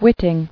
[wit·ting]